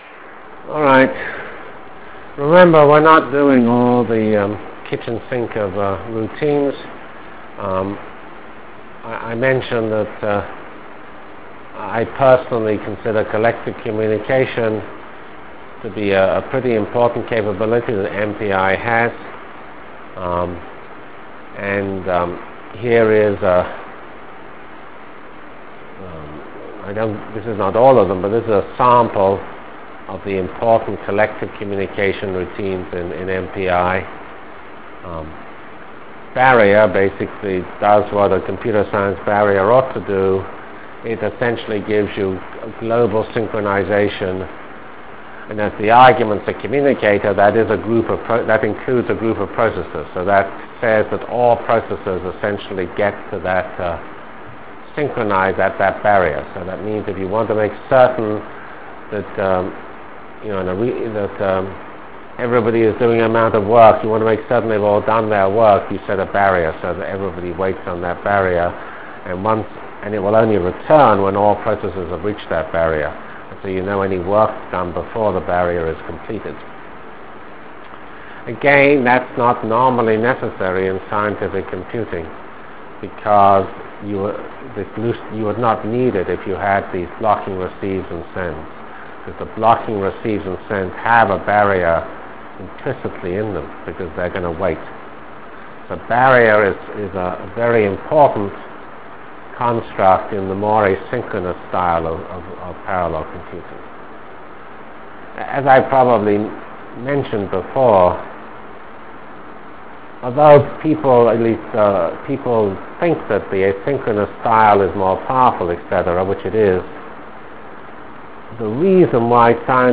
Lecture of November 7 - 1996